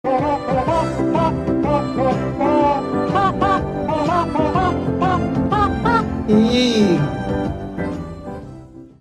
yee dinosaur meme Meme Sound Effect
yee dinosaur meme.mp3